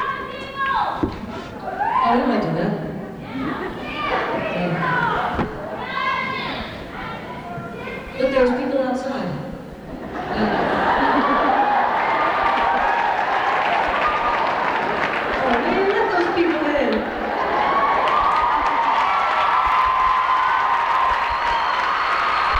lifeblood: bootlegs: 1990-12-08: smith college - northampton, massachusetts
(acoustic duo show)
12. talking with the crowd (0:22)